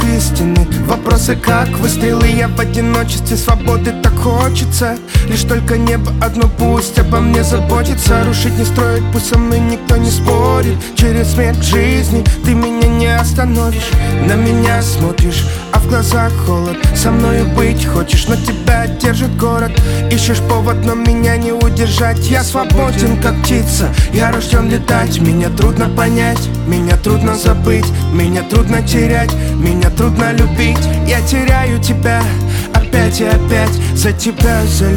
Hip-Hop Rap Singer Songwriter